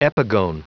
Prononciation du mot epigone en anglais (fichier audio)
Prononciation du mot : epigone